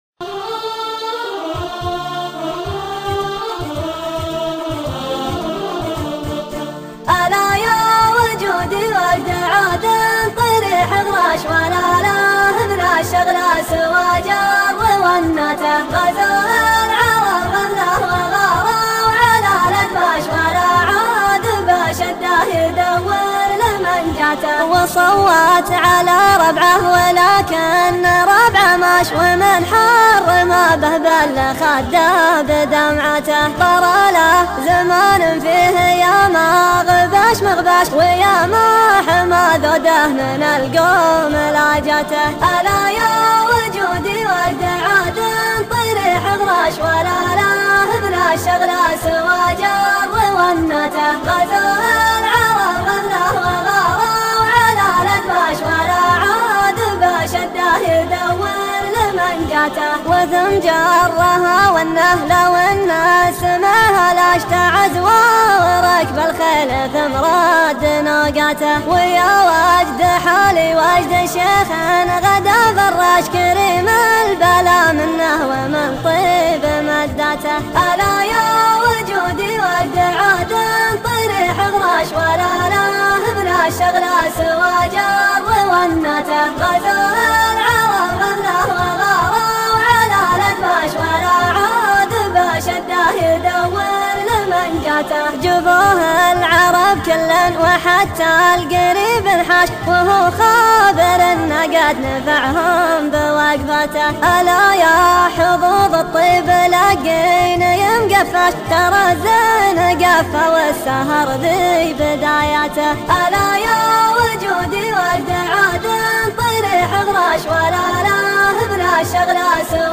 شيلة حزينة